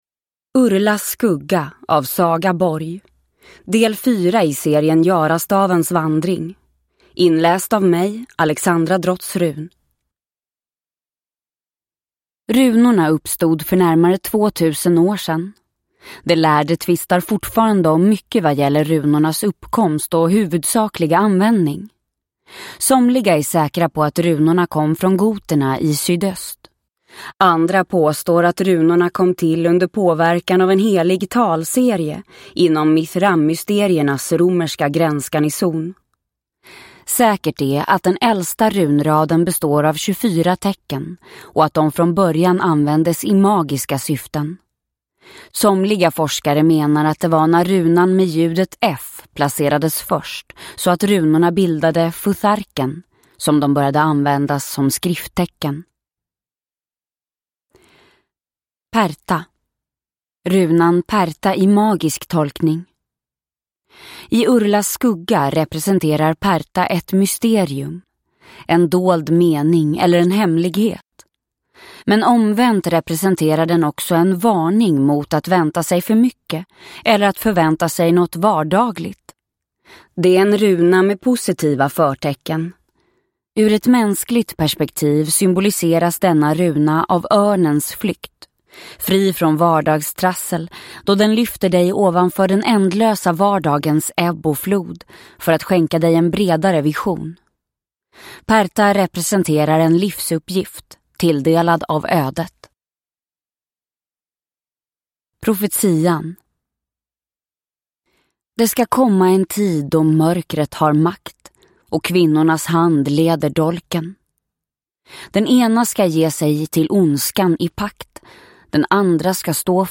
Urlas skugga – Ljudbok – Laddas ner